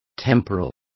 Complete with pronunciation of the translation of temporal.